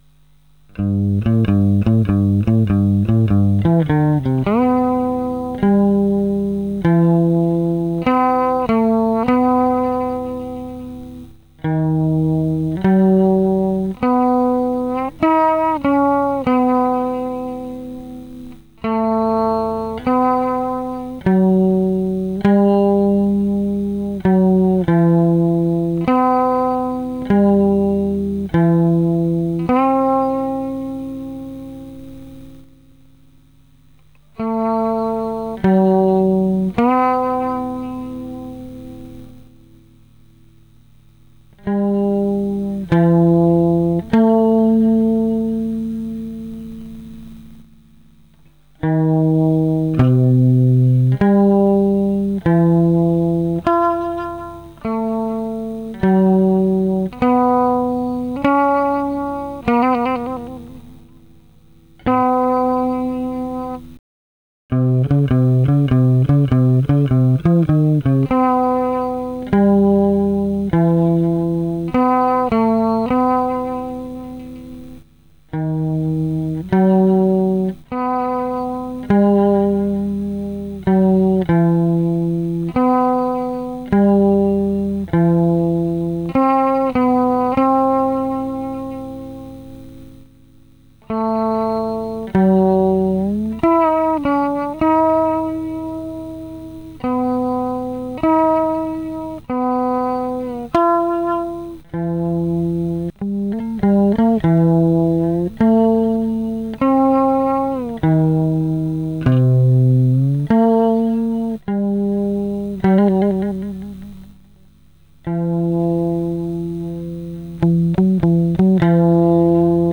10:45:36 » Честно - ожидал от звука большего, он не басовый и не гитарный, когда сам играешь это наверно прикольно и интересно, но на записи звучание как у совковой акустики с пьезиком, когда запись жосско обработали шумодавом Извини если что - сказал как думаю.